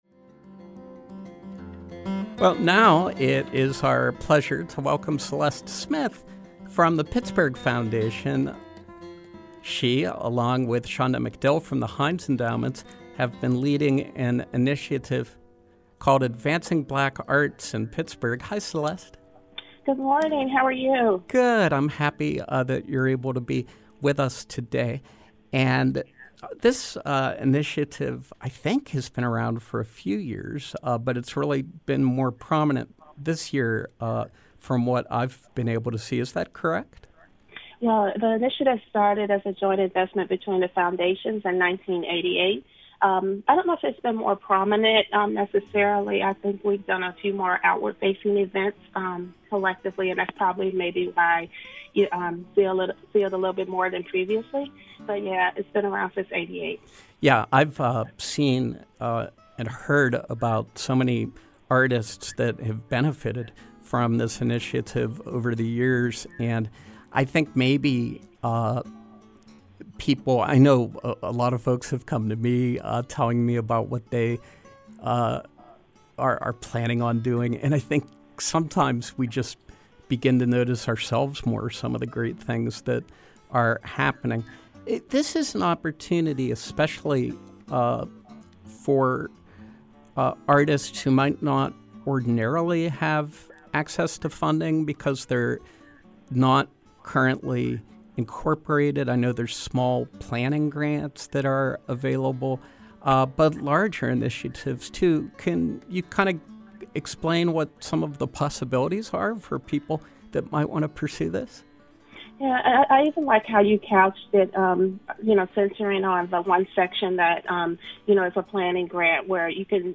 Home » Featured, Interviews